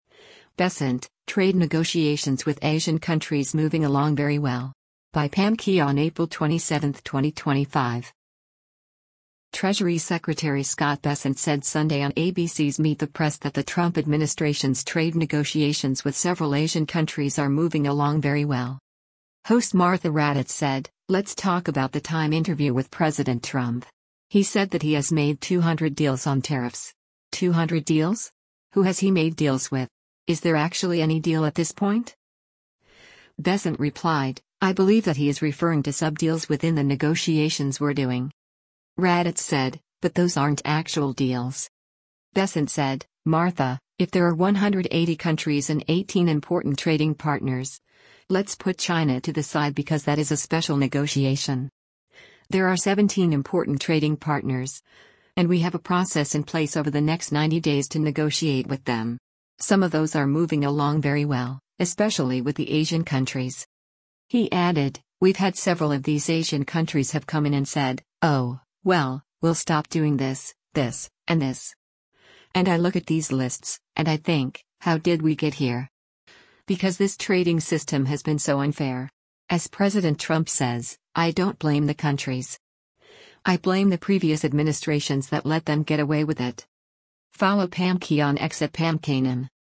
Treasury Secretary Scott Bessent said Sunday on ABC’s “Meet the Press” that the Trump administration’s trade negotiations with several Asian countries are “moving along very well.”